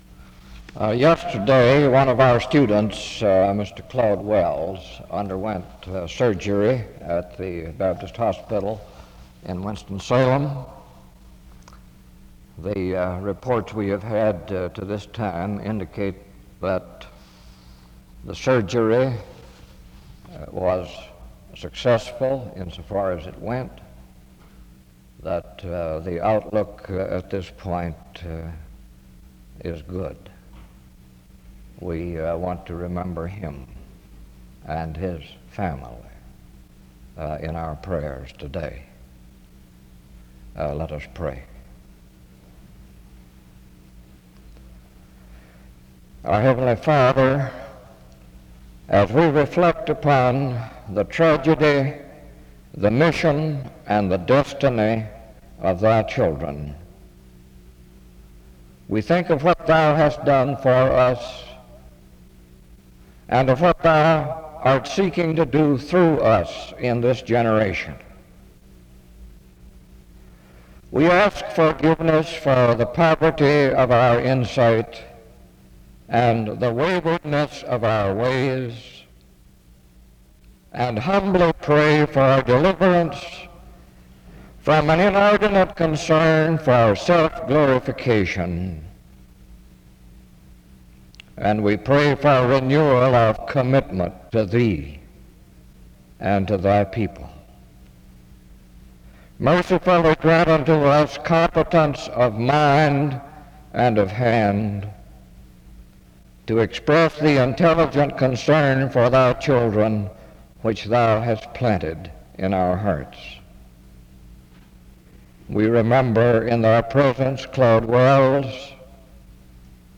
A responsive reading takes place from 3:04-4:54.
SEBTS Chapel and Special Event Recordings